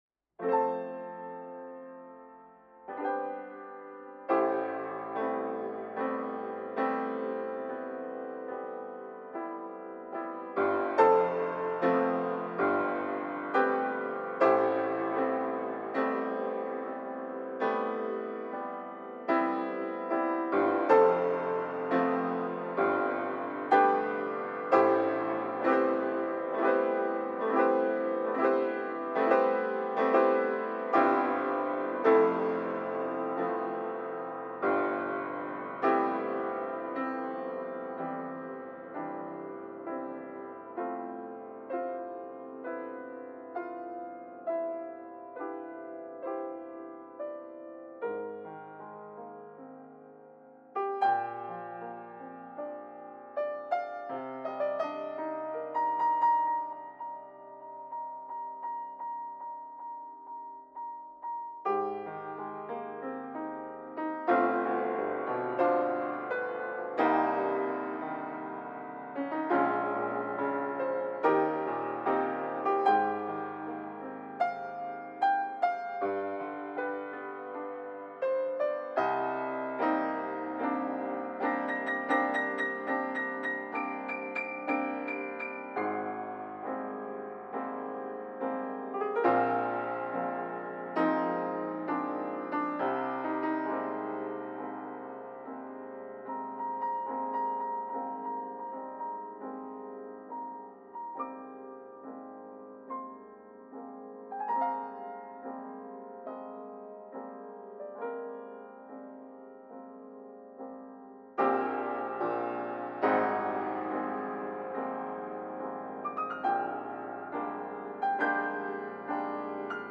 ピアノ即興